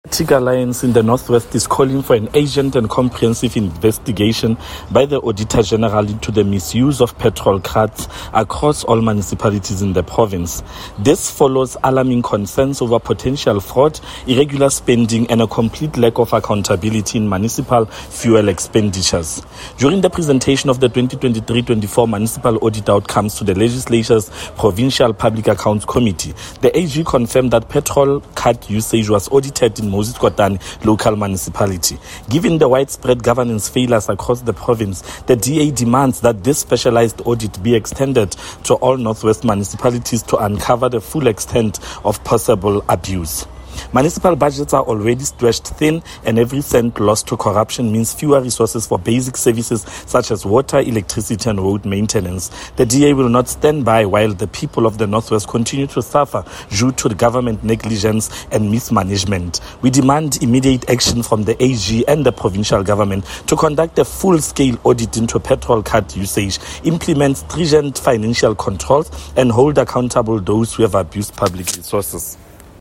Issued by Freddy Sonakile – DA Caucus Leader in the North West Provincial Legislature
Note to Broadcasters: Please find attached soundbites in